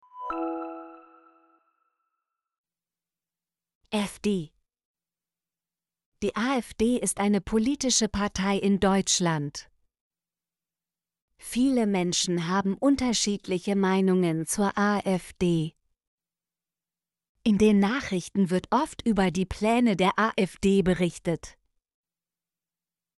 afd - Example Sentences & Pronunciation, German Frequency List